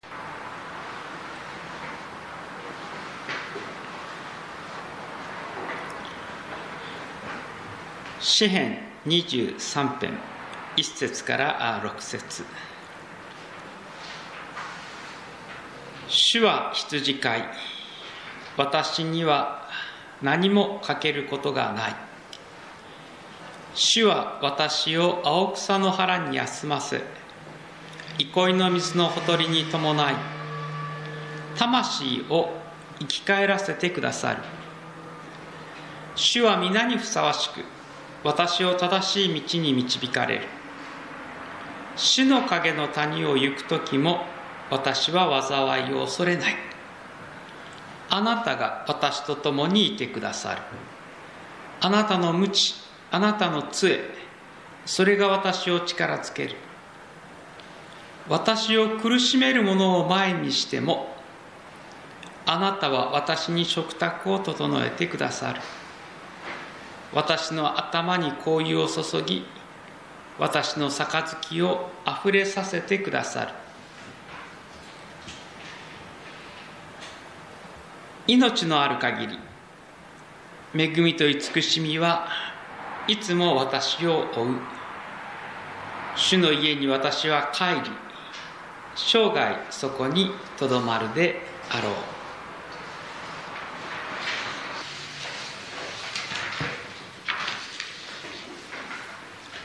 主は私の羊飼い(聖書朗読のみ)
２０２３年 10月29日 宇都宮教会主日礼拝